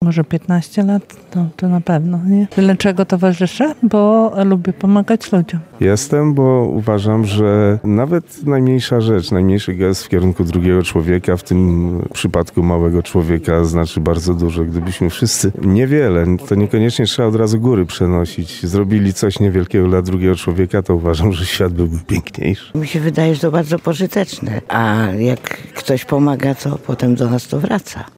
– Jestem, bo uważam, że nawet najmniejsza rzecz, najmniejszy gest w kierunku drugiego człowieka znaczy bardzo dużo – mówi jeden z wolontariuszy.